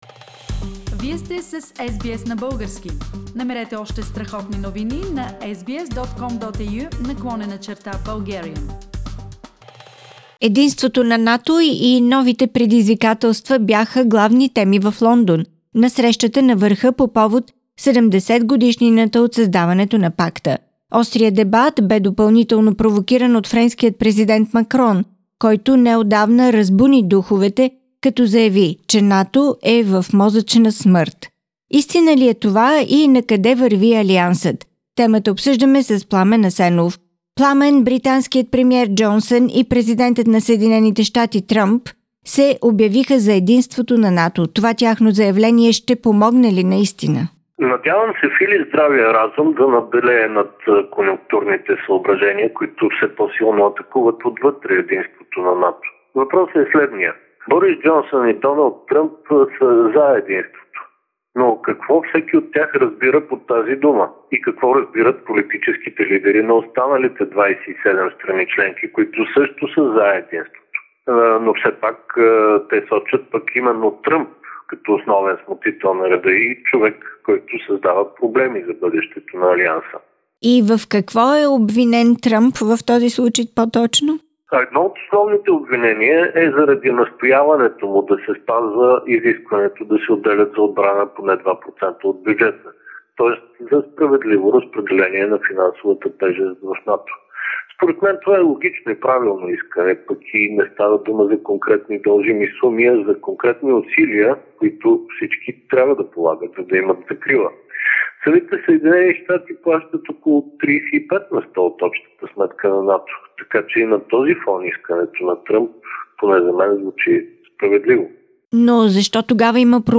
Политически анализ